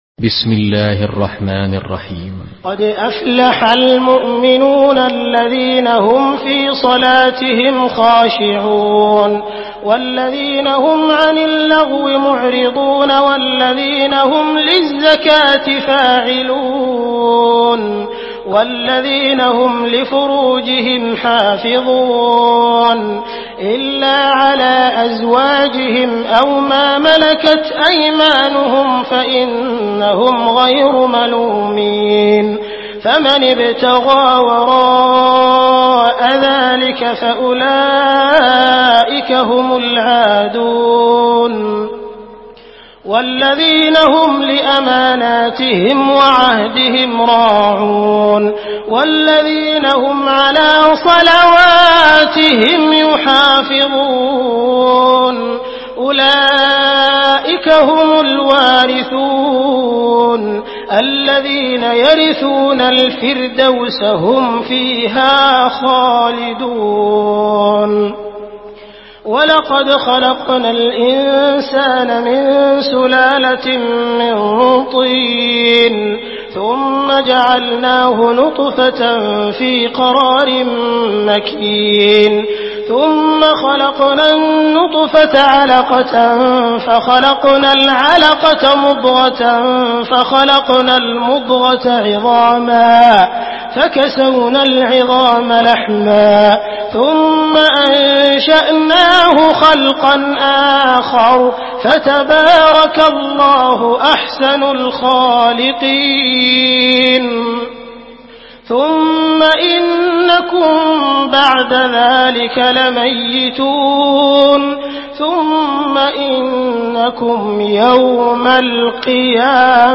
Surah Muminun MP3 by Abdul Rahman Al Sudais in Hafs An Asim narration.
Murattal Hafs An Asim